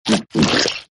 LizardLickLong.ogg